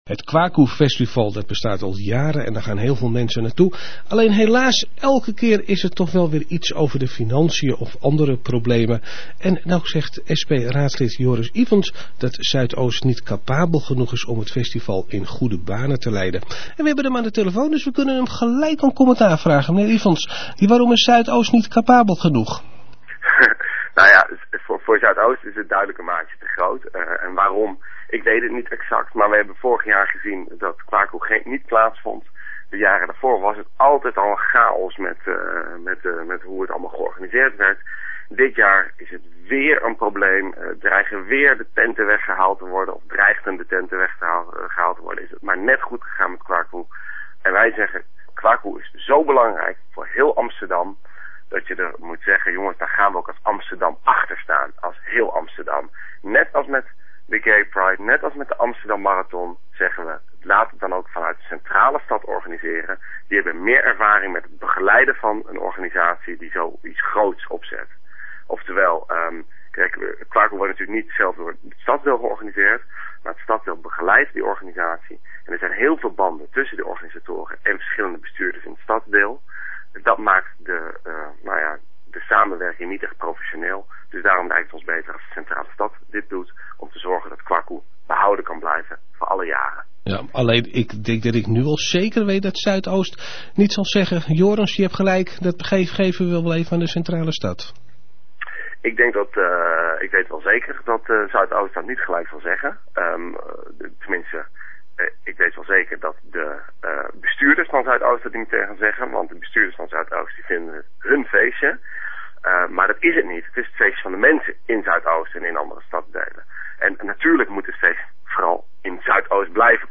Waarom hij dat vindt en of hij verwacht dat stadsdeel Zuidoost staat te juichen, dat vroegen wij aan het raadslid.